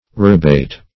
Search Result for " rabbate" : The Collaborative International Dictionary of English v.0.48: Rabbate \Rab*bate"\ (r[a^]b*b[=a]t"), v. t. [See Rabate .]